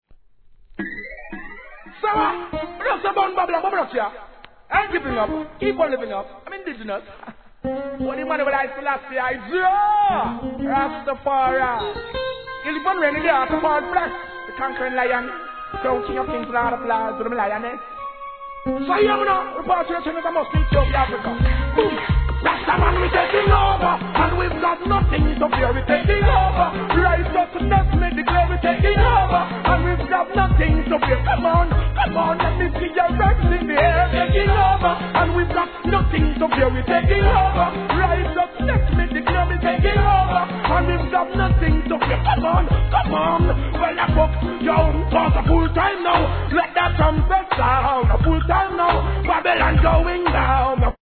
REGGAE
哀愁漂う